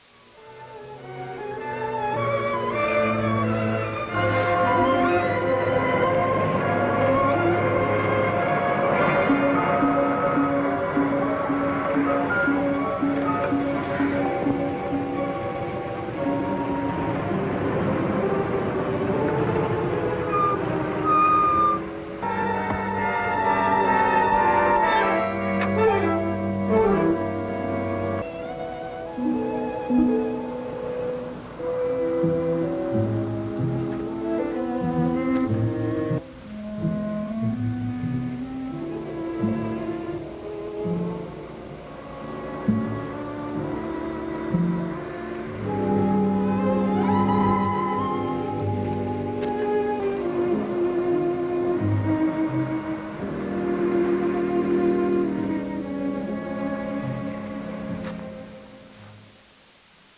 Incisiva colonna musicale
Track Music